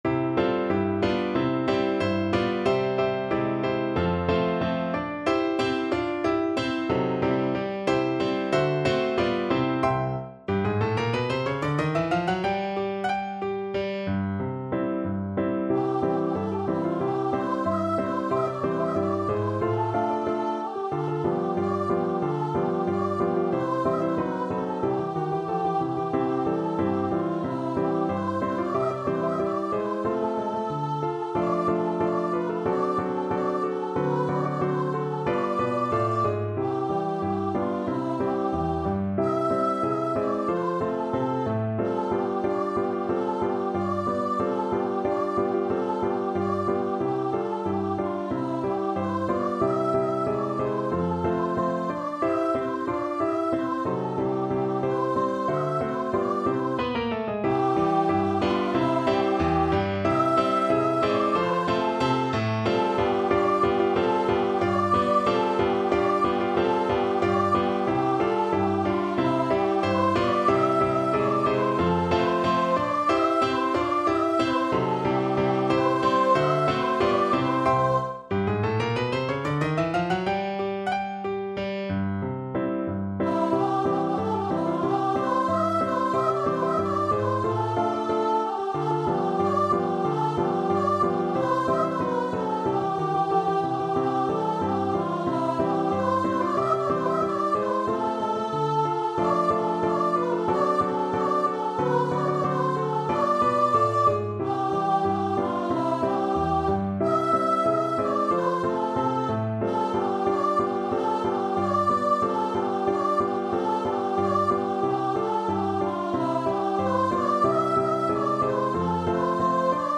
2/4 (View more 2/4 Music)
Allegretto =92
Voice  (View more Easy Voice Music)
Traditional (View more Traditional Voice Music)